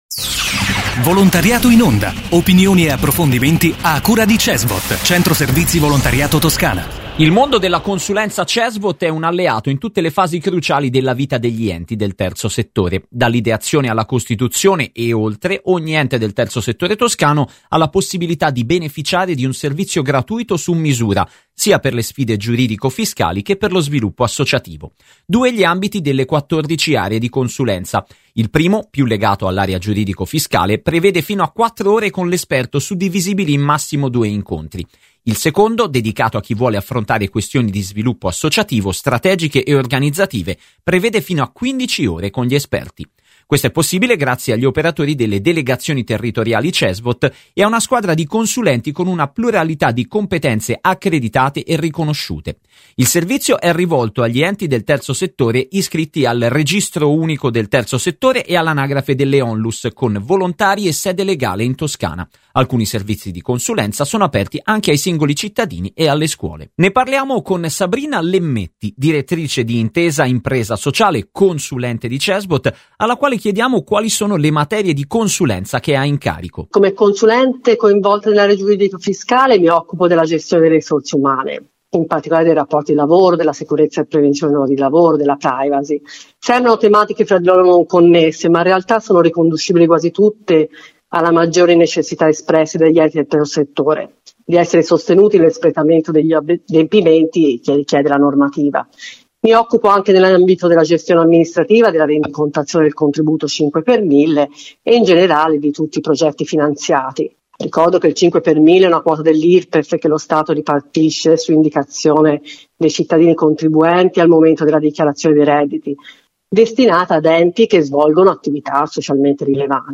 Il mondo della consulenza Cesvot è un alleato in tutte le fasi cruciali della vita degli enti del terzo settore. Dall'ideazione alla costituzione, e oltre, ogni ente del terzo settore toscano ha la possibilità di beneficiare di un servizio gratuito su misura, sia per le sfide giuridico-fiscali che per lo sviluppo associativo. Intervista